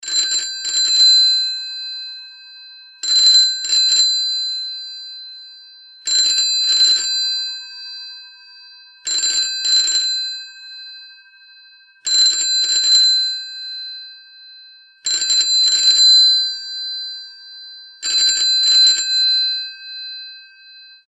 What I have is a type 746 - the GPO’s last offering that had a proper bell (listen
tele746_with_band.mp3